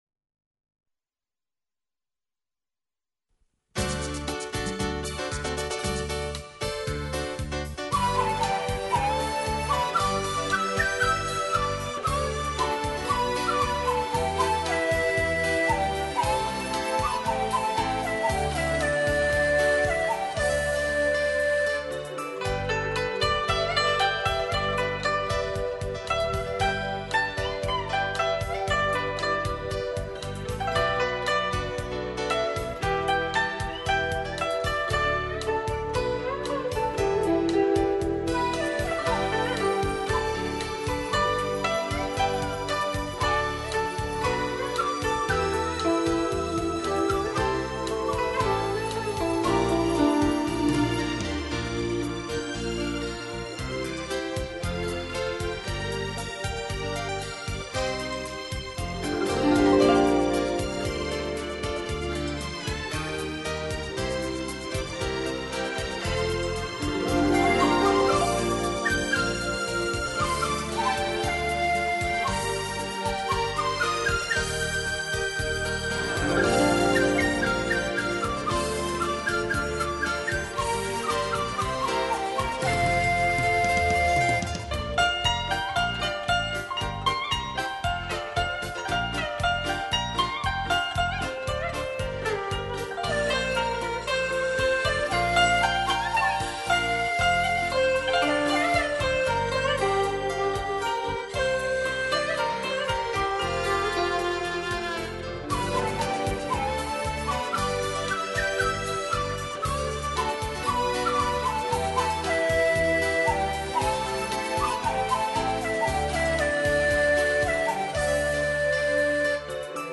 无论是萨克斯的婉约，还是钢琴的浑厚和电子琴的清越，亦或古筝的凝重
古筝+钢琴+电子琴